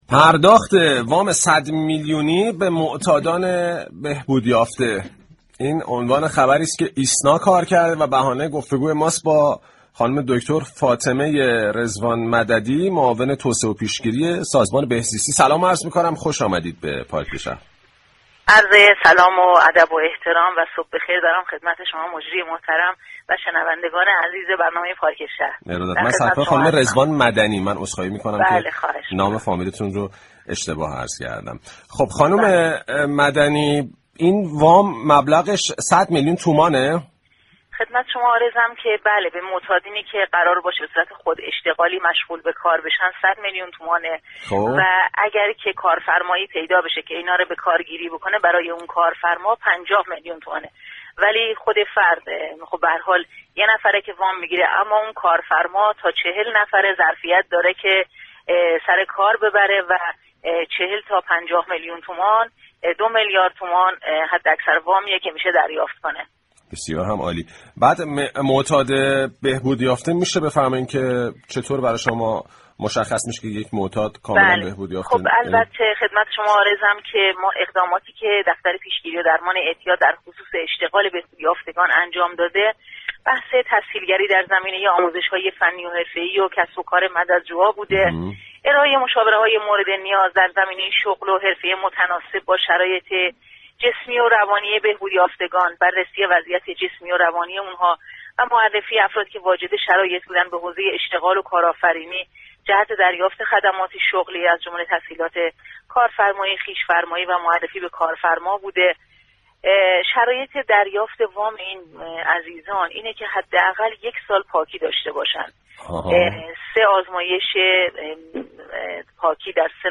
به گزارش پایگاه اطلاع رسانی رادیو تهران، فاطمه رضوان مدنی سرپرست مركز توسعه پیشگیری و درمان اعتیاد سازمان بهزیستی كشور در گفتگو با پارك شهر رادیو تهران، درخصوص افزایش مبلغ وام خود اشتغالی به معتادان بهبودیافته گفت: به معتادانی كه قرار است به شكل خوداشتغالی مشغول به كار شوند 100 میلیون تومان و اگر كارفرمایی بخواهد این افراد را استخدام كند به ازای هر فرد 50 میلیون تومان وام پرداخت می‌شود.